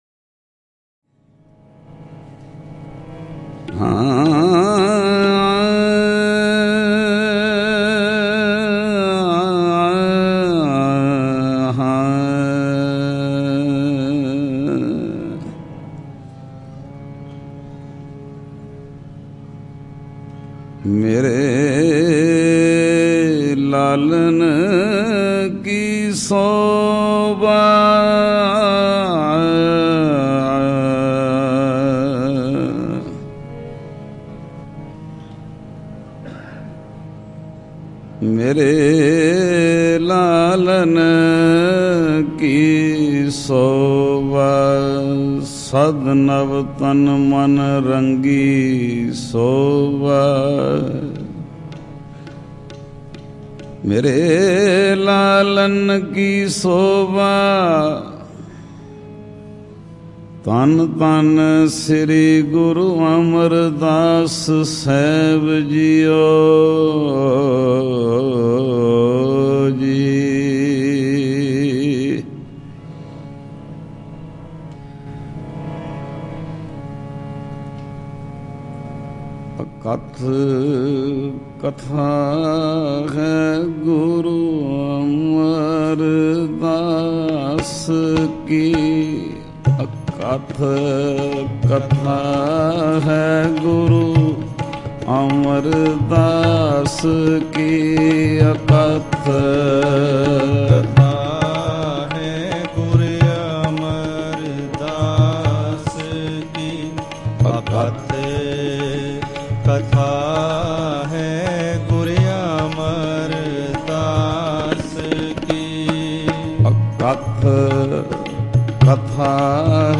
Shabad Gurbani Kirtan Album Info